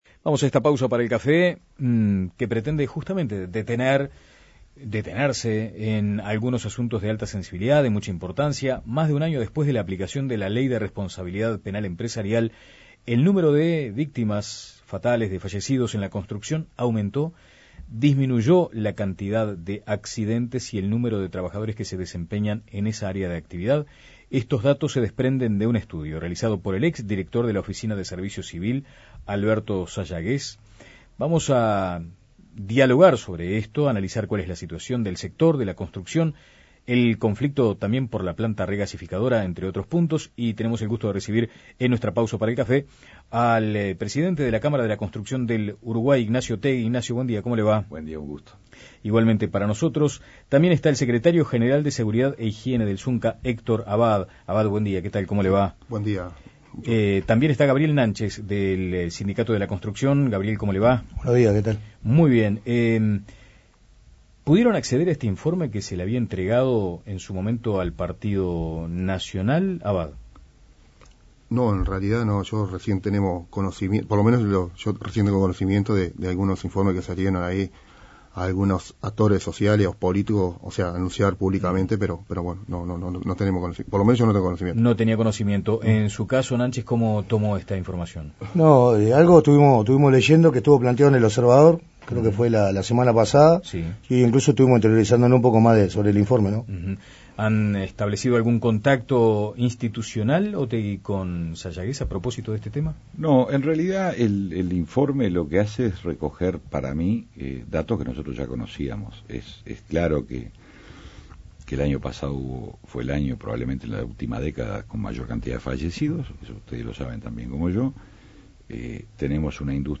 conversaron en La Mañana de El Espectador